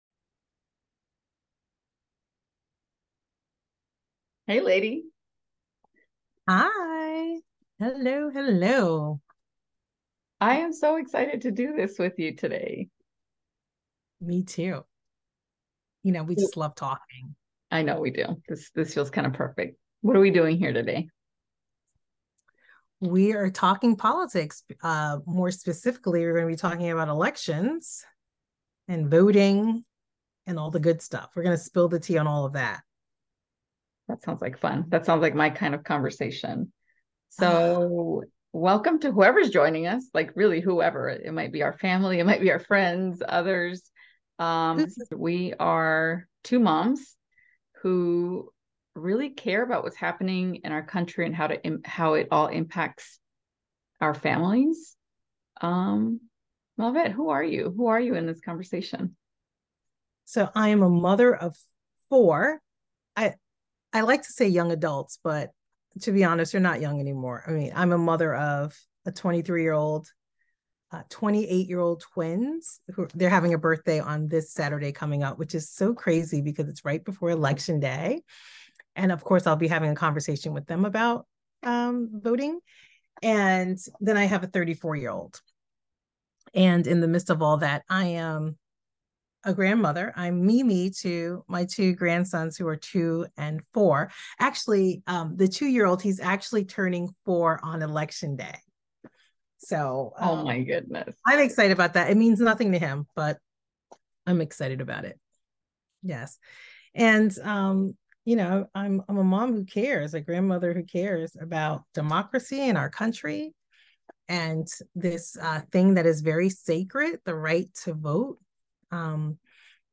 two moms and parent leaders from California and Connecticut